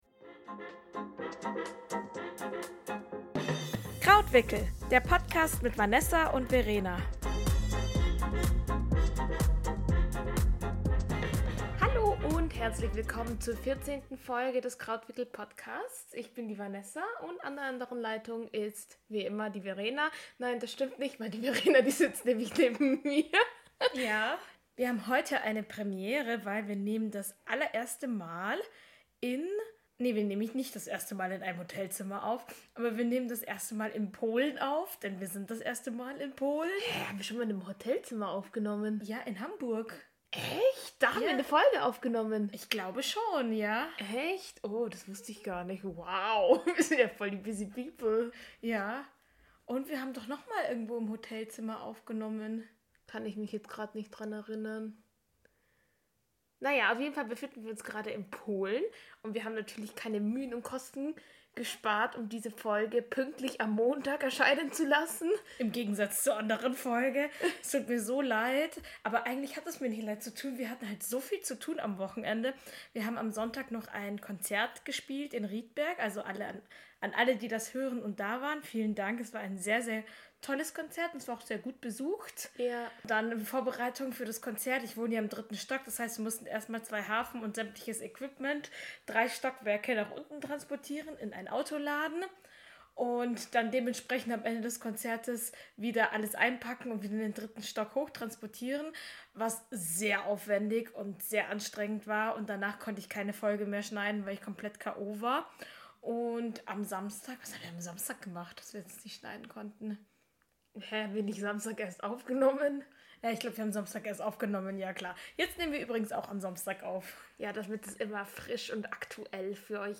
Die heutige Folge wurde hochwertig in einem polnischem Hotel für euch produziert. Wie und Warum wir hier gelandet sind, erfahrt ihr in der folgenden Episode.